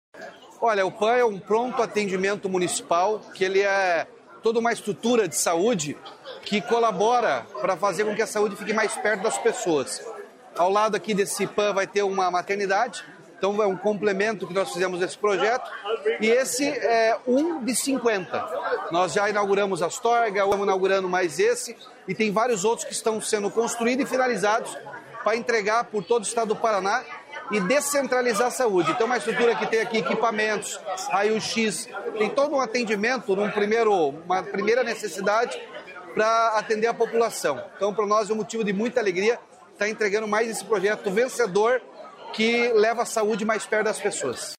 Sonora do governador Ratinho Junior sobre o novo pronto atendimento de Bela Vista do Paraíso